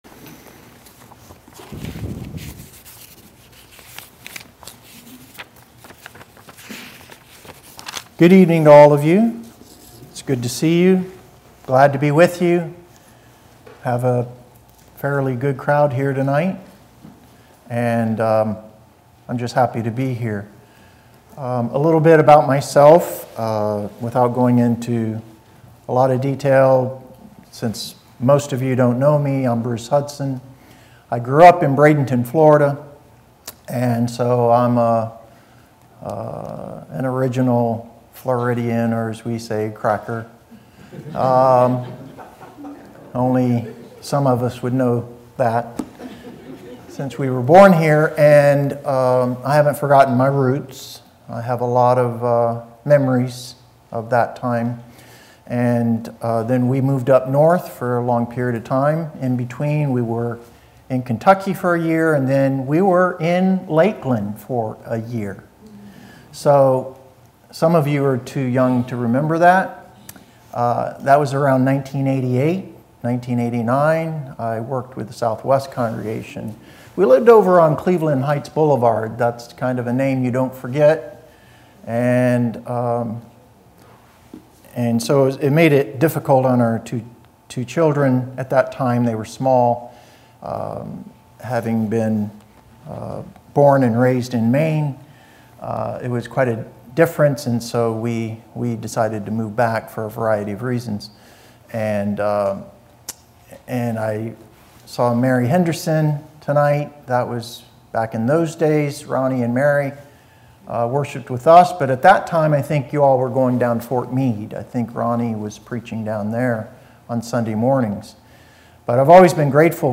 Sun PM Worship – Facing Impossible Situations